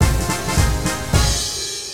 add win and game over music